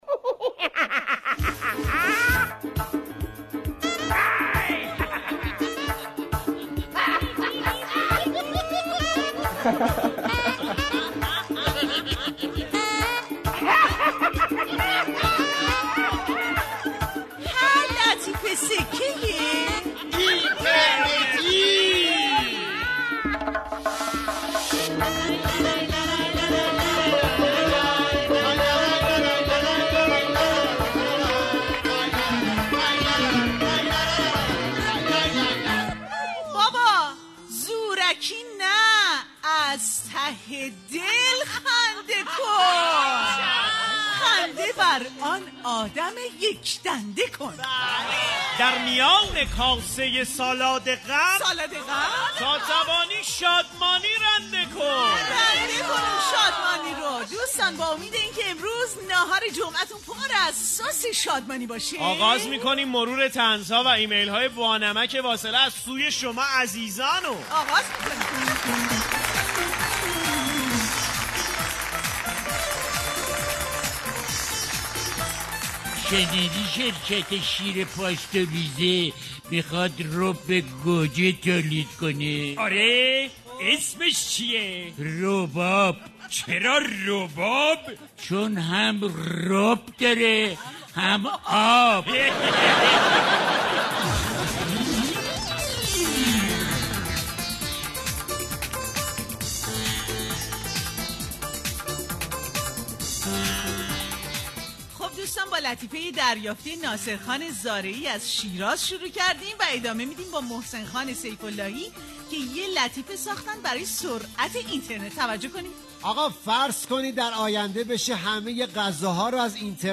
• دفتر خاطرات, شاعر یه ربع یه ربع, جمعه ایرانی, لطیفه های اینترنتی, جک های جدید, اخبار در 60 ثانیه, مسابقه شاباش, آقای مثبت اندیش, ترانه های شاد, موزیک های شاد, دانلود جمعه ایرانی, رادیو ایران, لطیفه های با مزه, برنامه طنز رادیو ایران, پ ن پ, گیر بازار